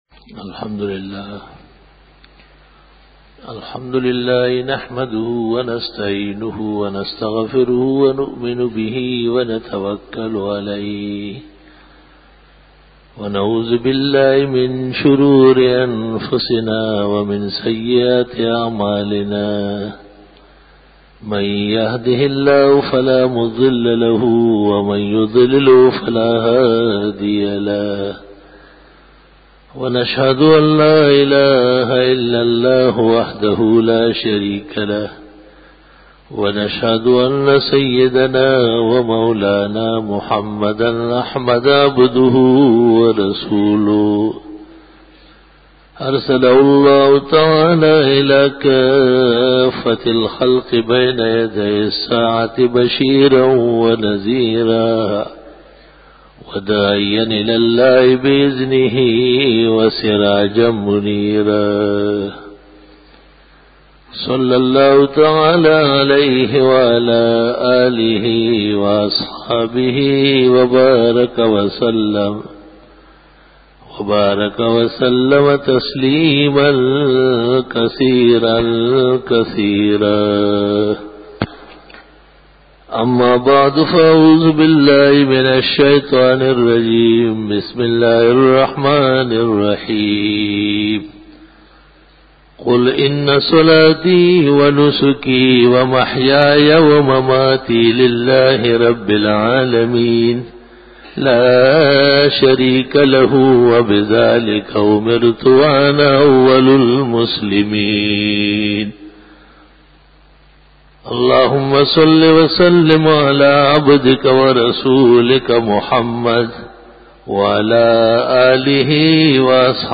بیان جمعۃ المبارک یکم مارچ 2002